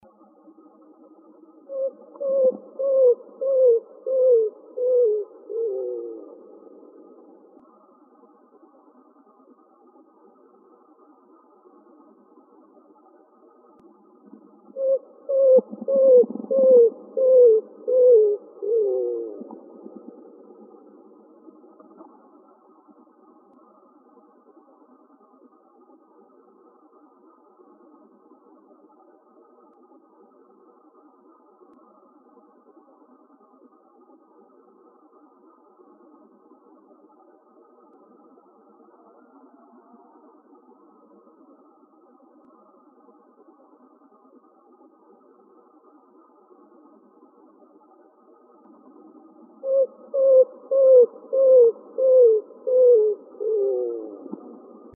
greaterroadrunner.wav